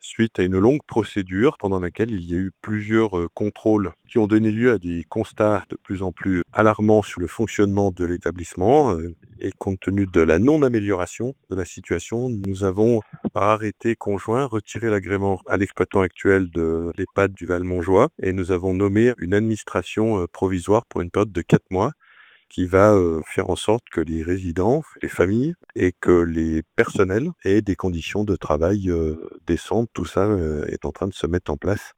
ITC Martial Saddier 1-Fermeture Ehpad Val Montjoie St Gervais